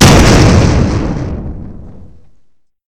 extinguisher_explosion.1.ogg